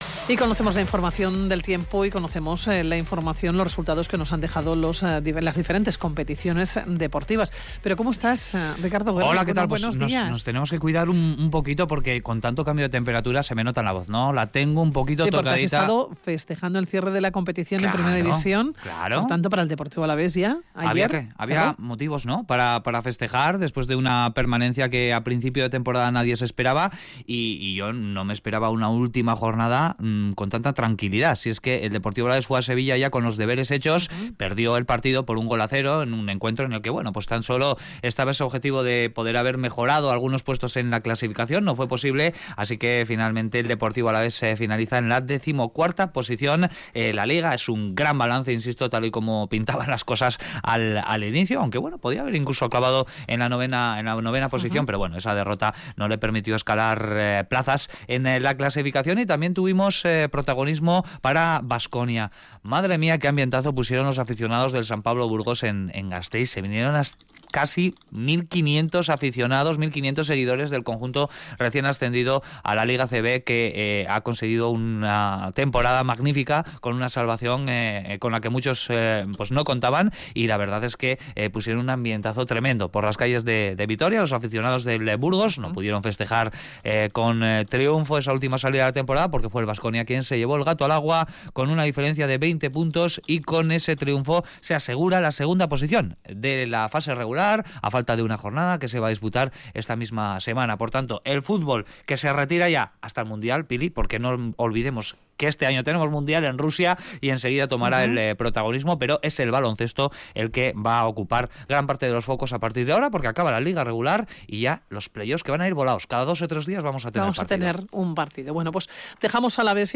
Audio: En el espacio "Zona mixta", de Radio Vitoria Gaur Magazine, hablamos con los responsables de Sport and Play, un nuevo proyecto que se ha presentado en Gasteiz.